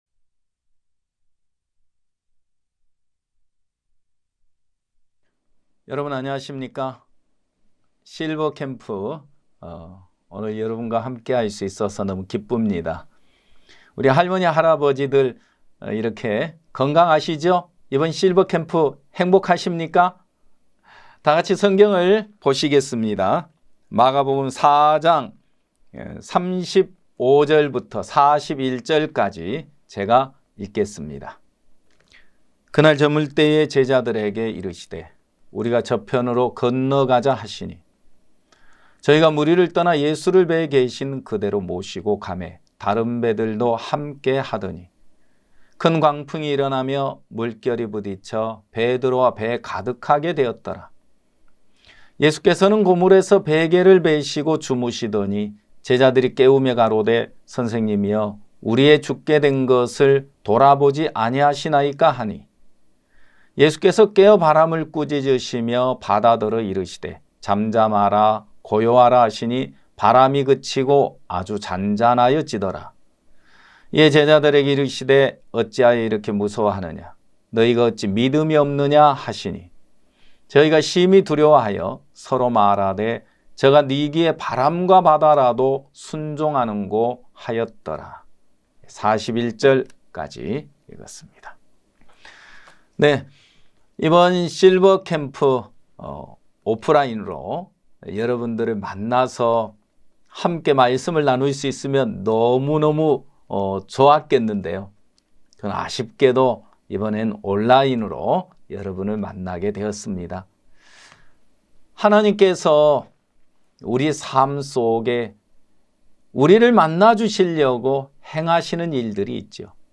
매해 여름과 겨울, 일 년에 두 차례씩 열리는 기쁜소식선교회 캠프는 아직 죄 속에서 고통 받는 사람들에게는 구원의 말씀을, 일상에 지치고 마음이 무뎌진 형제자매들에게는 기쁨과 평안을 전하고 있습니다. 매년 굿뉴스티비를 통해 생중계 됐던 기쁜소식 선교회 캠프의 설교 말씀을 들어보세요.